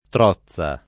trozza [ tr 0ZZ a ]